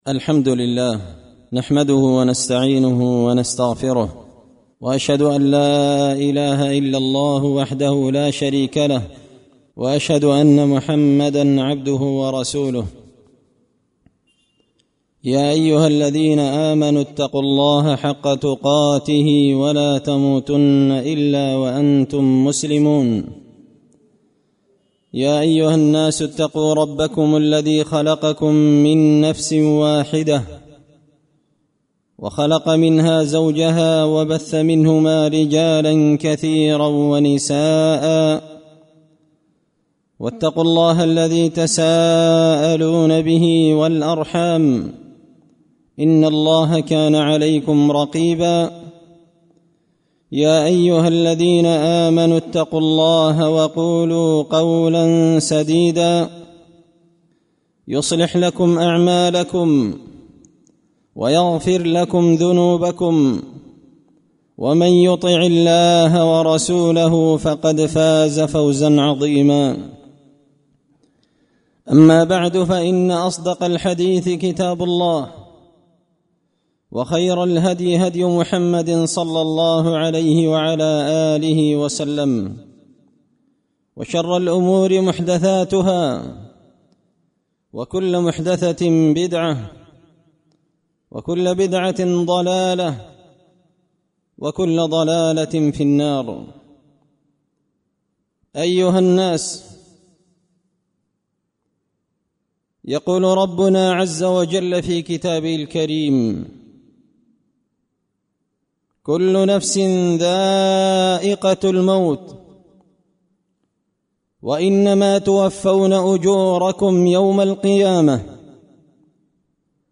خطبة جمعة بعنوان – كل نفس دائقة الموت
دار الحديث بمسجد الفرقان ـ قشن ـ المهرة ـ اليمن